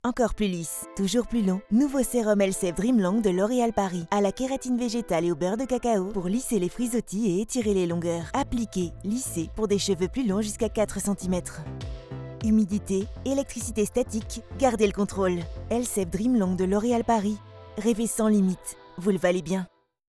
Voix Off femme en home studio
Timbre médium
Souriante et pétillante
Le tout en qualité Broadcast, grâce à mon home studio insonorisé et traité acoustiquement, équipé de matériel professionnel.
Micro Neumann TLM 103 – Interface audio SSL 2
COVERS Pub TV (non diffusés)